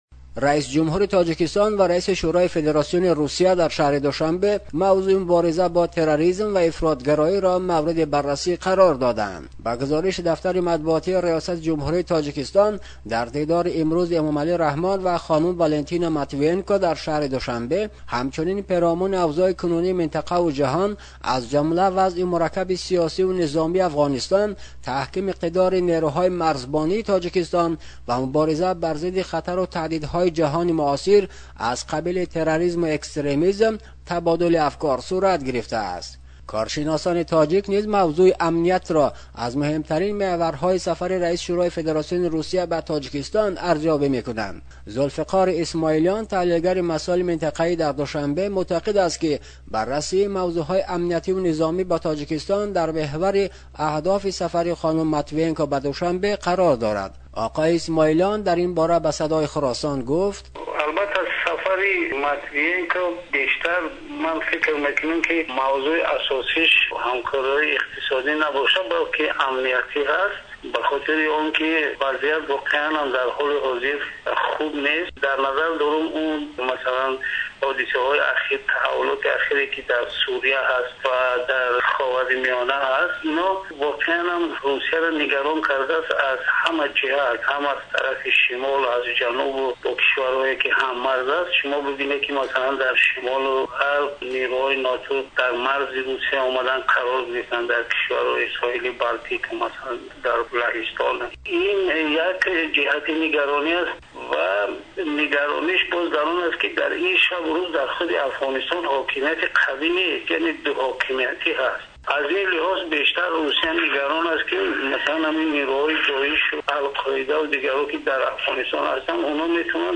гузориш медиҳад